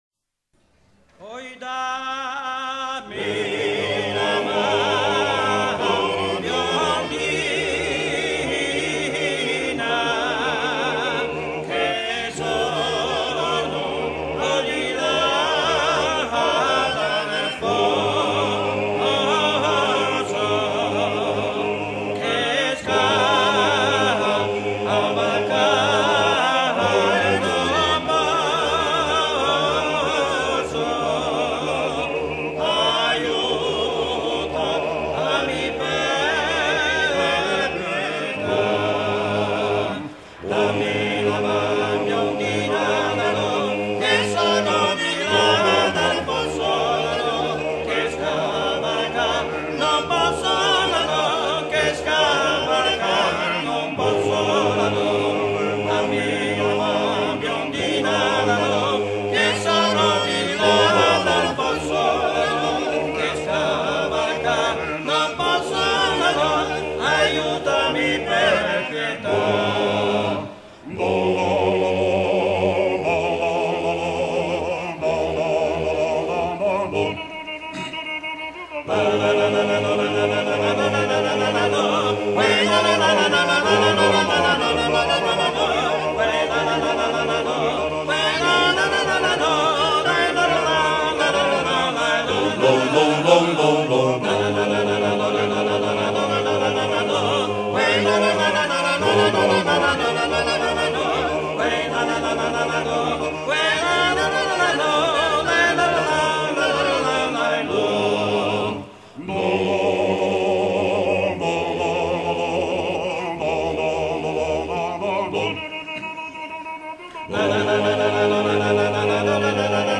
trallalero
Pièce musicale éditée